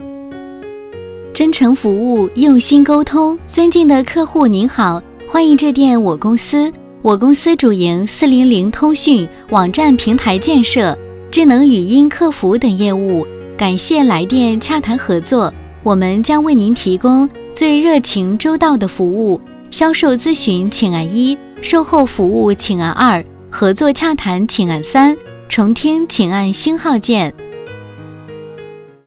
400电话彩铃和导航功能展示